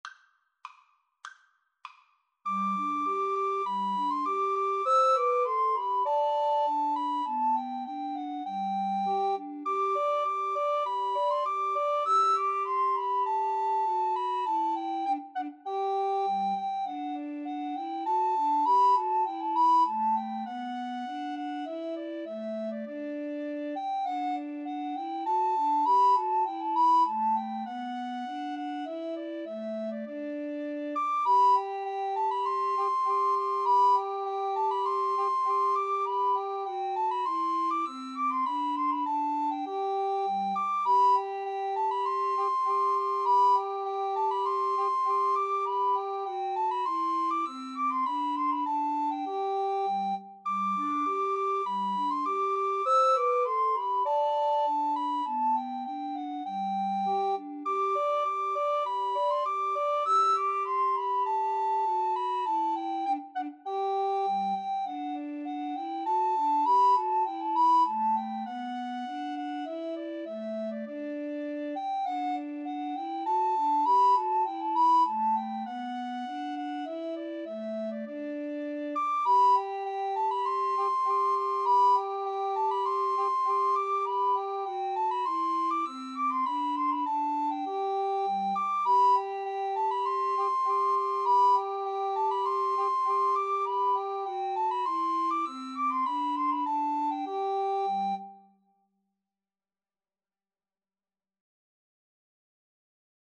Free Sheet music for Recorder Trio
G major (Sounding Pitch) (View more G major Music for Recorder Trio )
2/2 (View more 2/2 Music)
Traditional (View more Traditional Recorder Trio Music)